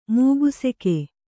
♪ mūgu seke